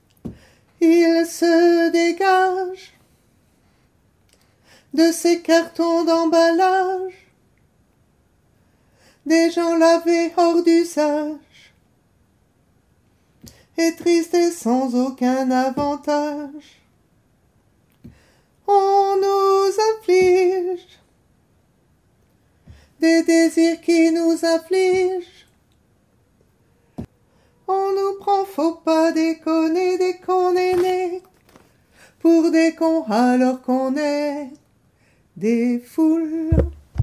Couplet 2 basses